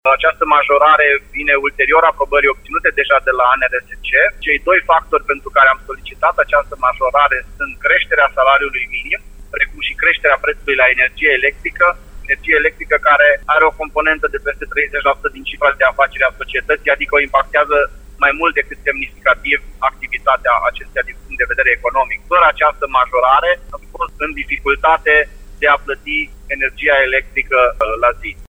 Majorarea este justificată de creșterea cheltuielilor societății de apă și canal, care se află în insolvență, spune primarul Lugojului, Claudiu Buciu: